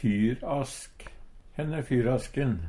DIALEKTORD PÅ NORMERT NORSK fyrask fyrstikkeske Eintal ubunde Eintal bunde Fleirtal ubunde Fleirtal bunde Eksempel på bruk Henn e fyrasken?